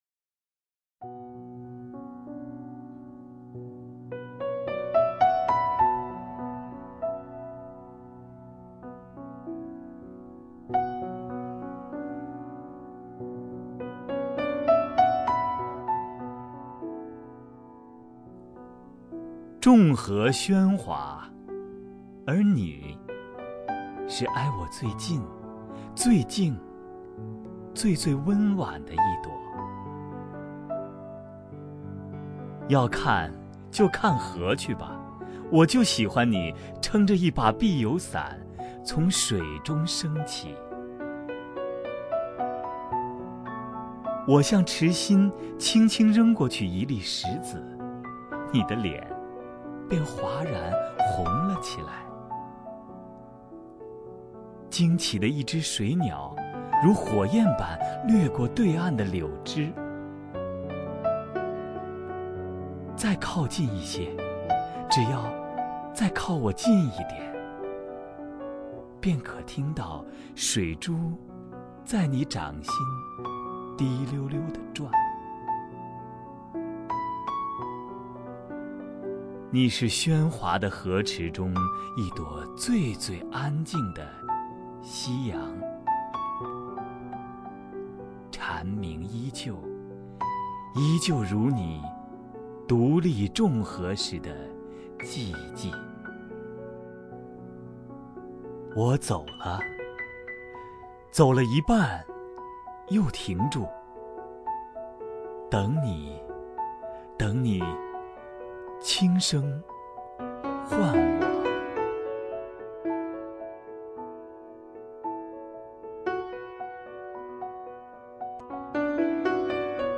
康辉朗诵：《众荷喧哗》(洛夫)